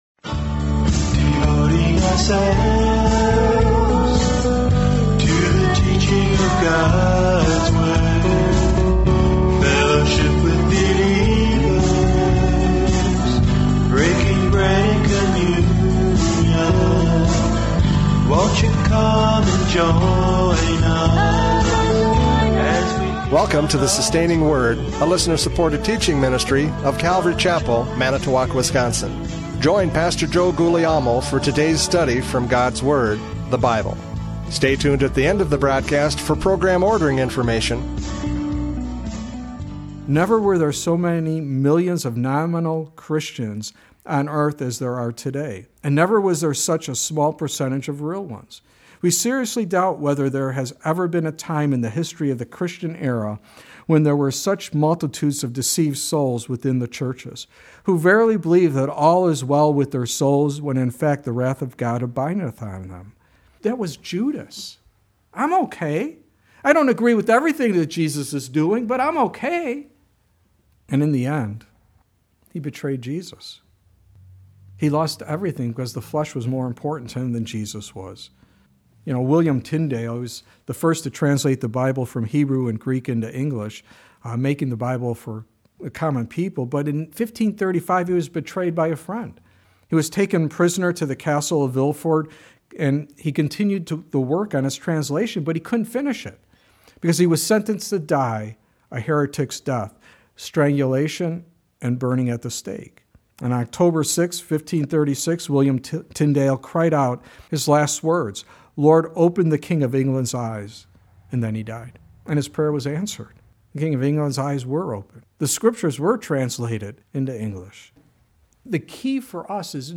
John 13:21-30 Service Type: Radio Programs « John 13:21-30 The Betrayer!